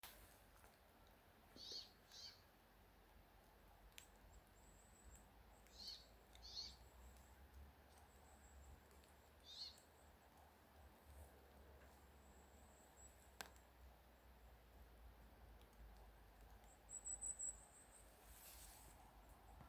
Putni -> Žubītes ->
Ziemas žubīte, Fringilla montifringilla